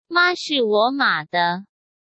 Mā shì wǒ mǎ de.